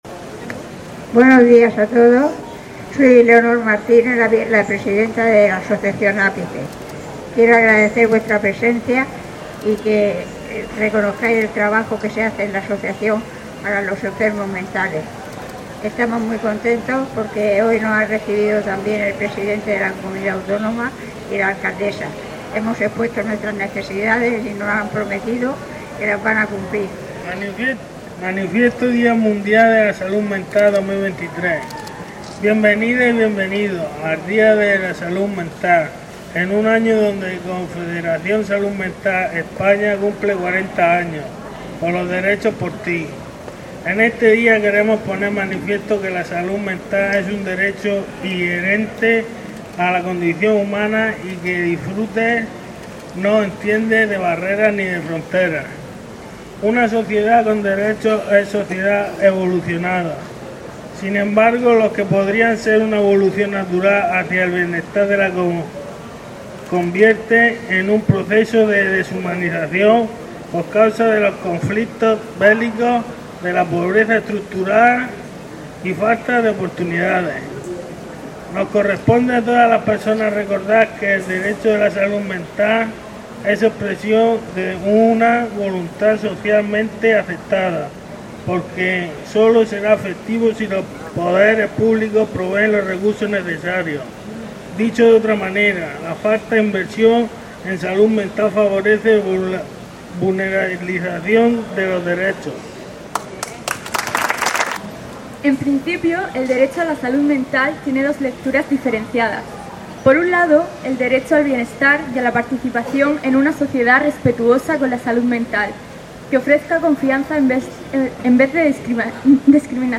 Audio: Declaraciones de la alcaldesa Noelia Arroyo y el presidente regional Fernando L�pez Miras.